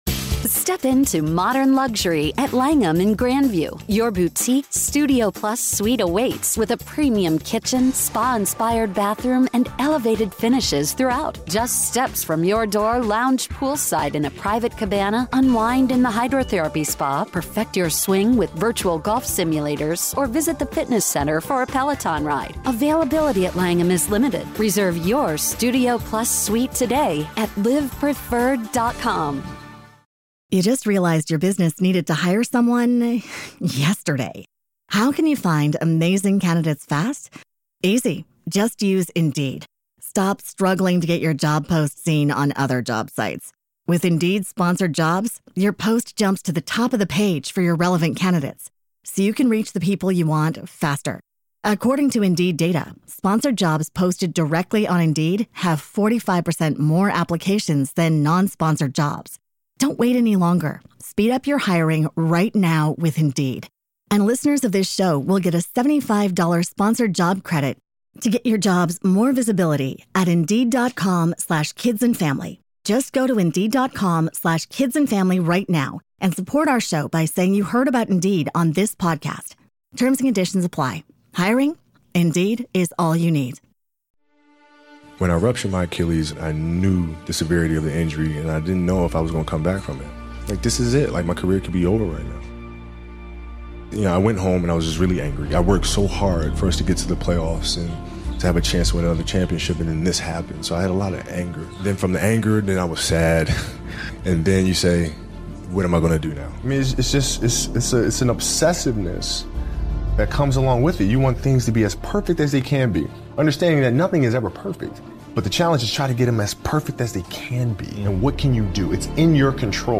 This motivational speech compilation features speeches from Kobe Bryant